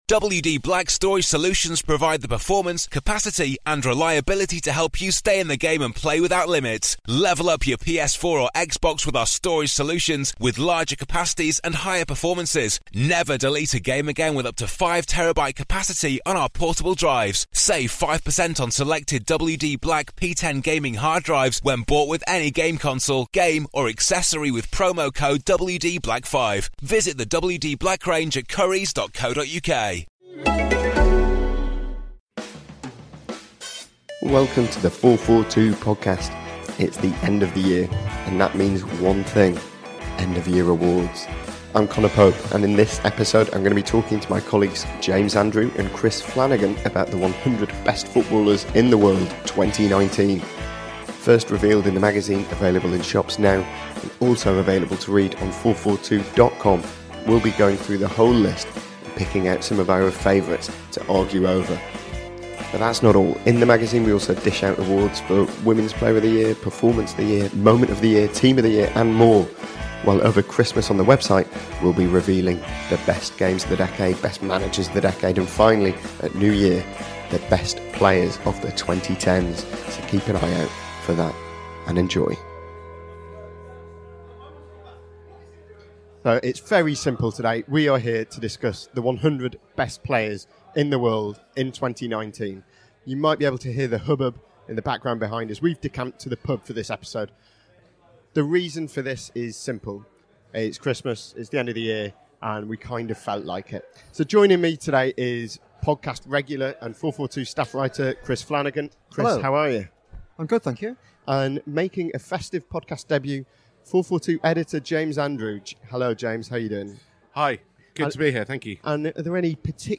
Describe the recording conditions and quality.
We’ve decamped to the pub for this episode.